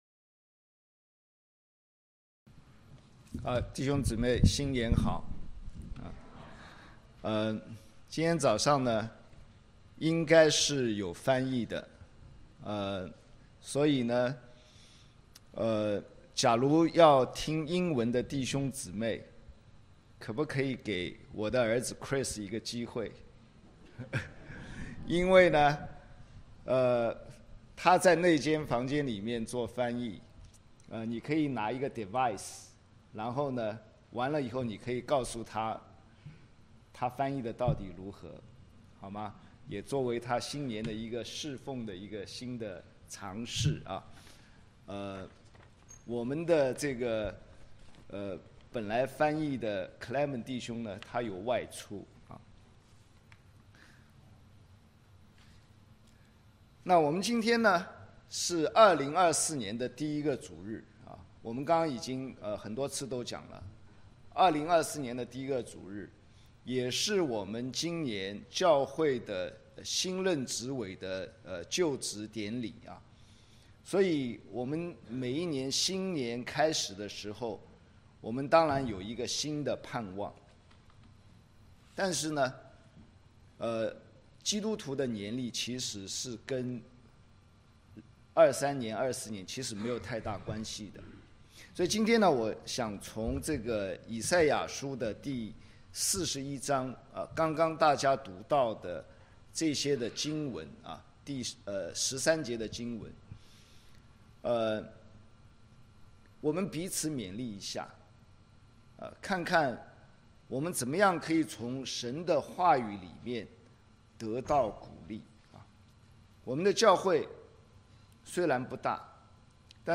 以賽亞書 Passage: 以賽亞書41: 8-20 Service Type: 三堂聯合崇拜 - 國語 41:8 惟你以色列我的僕人、雅各我所揀選的、我朋友亞伯拉罕的後裔、 41:9 你是我從地極所領〔原文作抓〕來的、從地角所召來的、且對你說、你是我的僕人、我揀選你並不棄絕你。